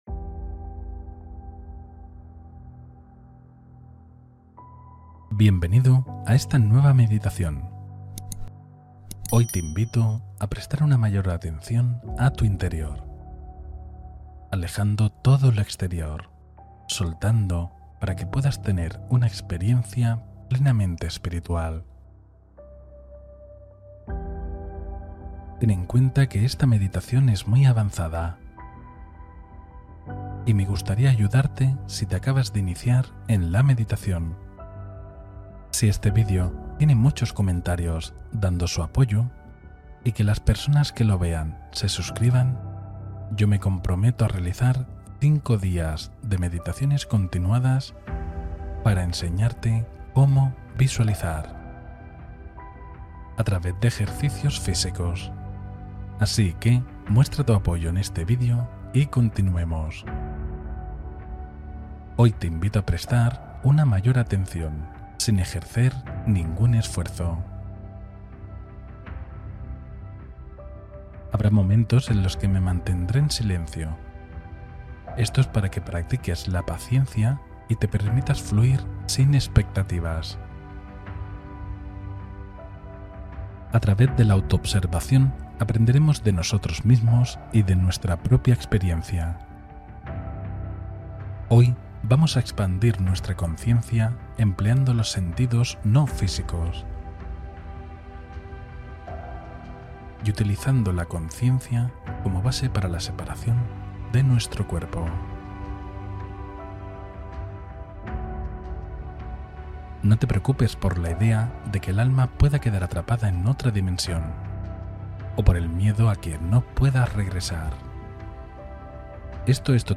Vipassana Experiencial: Meditación de Observación Profunda del Presente